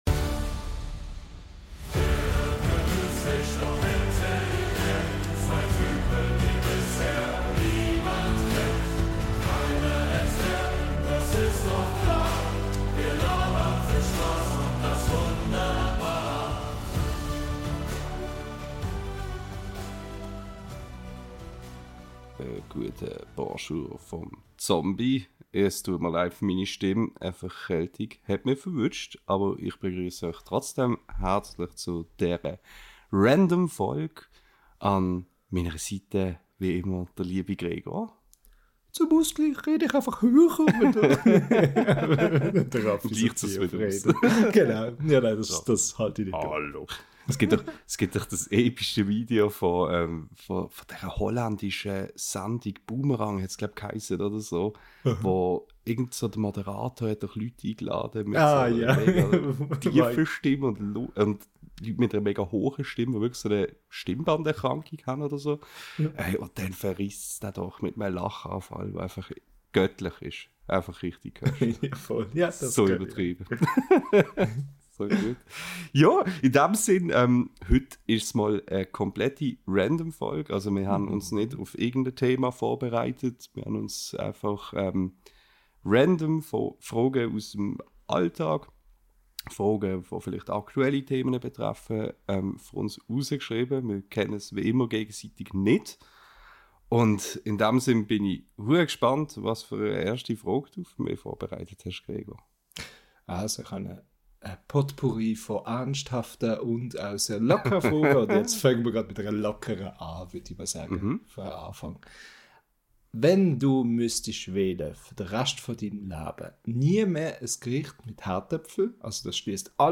In dieser Folge unseres schweizerdeutschen Podcasts brechen wir mit dem gewohnten Konzept und werfen uns stattdessen völlig zufällige Fragen an den Kopf – eben ganz random.